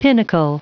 Prononciation du mot pinnacle en anglais (fichier audio)
Prononciation du mot : pinnacle